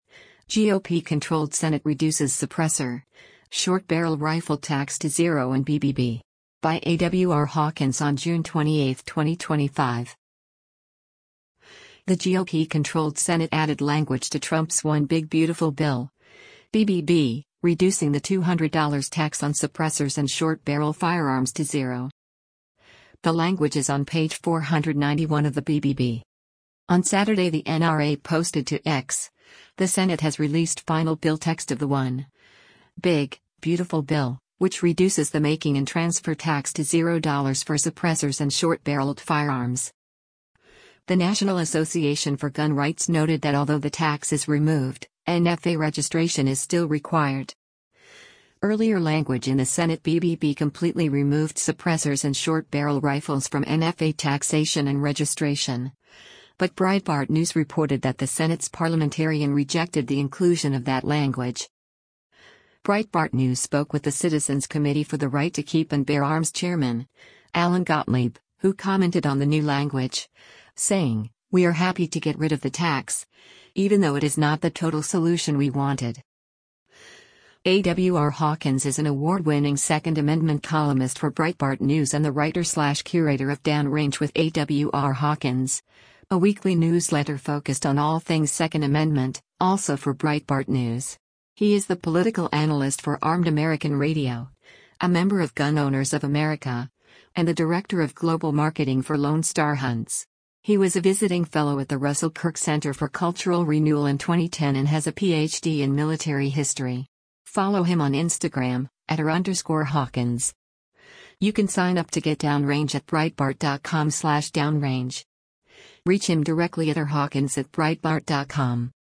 demonstrates shooting with a suppressor